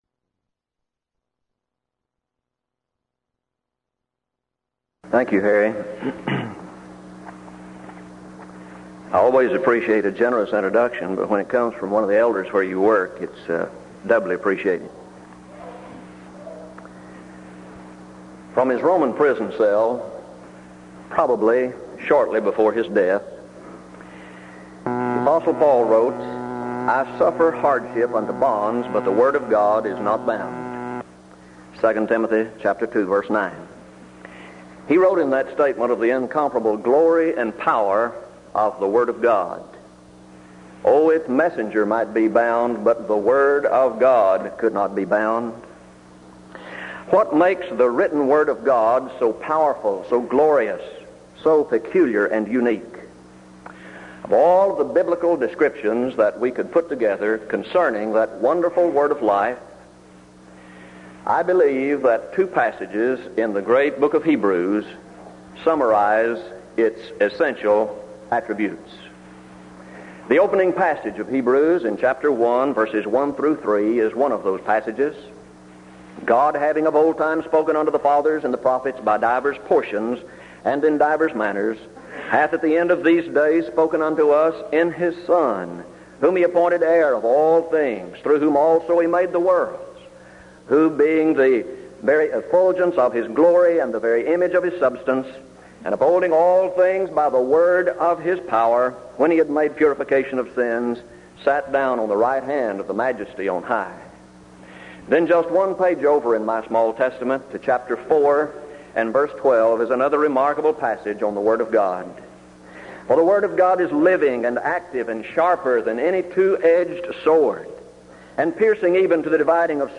Event: 1983 Denton Lectures Theme/Title: Studies in Hebrews